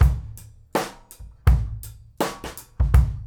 GROOVE 9 08L.wav